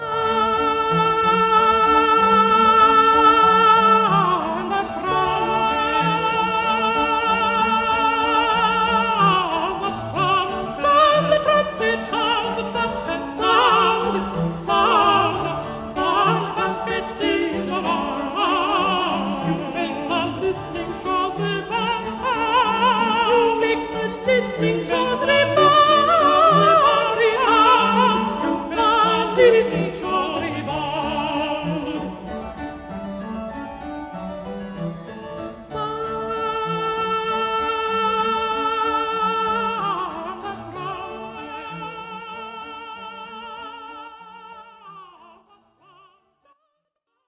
Alfred-Deller-Purcell-Sound-the-trumpet-2.mp3